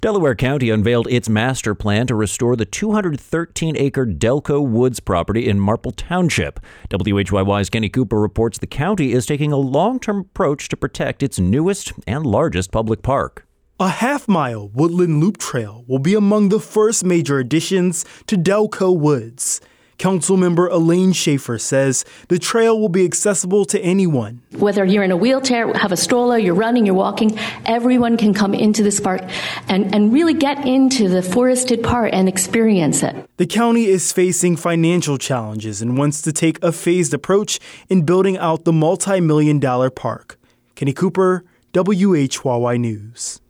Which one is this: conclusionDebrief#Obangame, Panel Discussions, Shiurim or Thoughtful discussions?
Thoughtful discussions